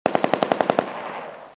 Ak 47 Sound